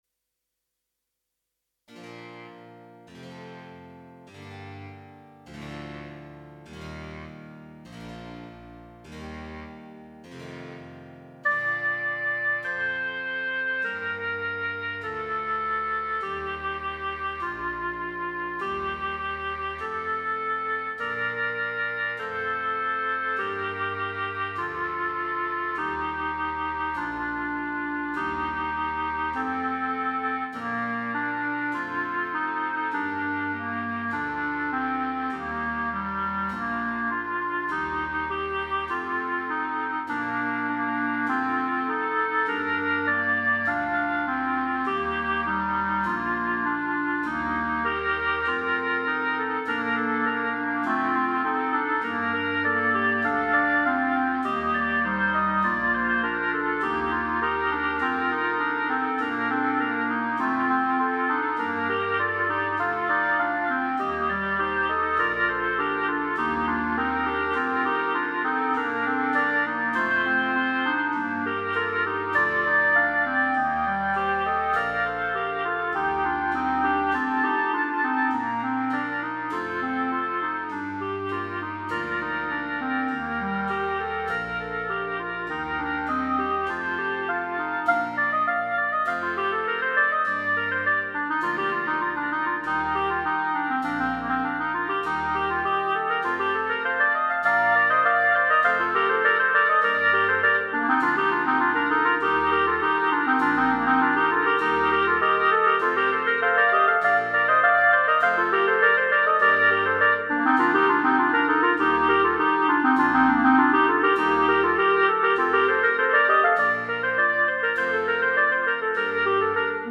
Concert Bb Edition
Instrumentation:3 Bb Clarinet, Bs Cl, opt cello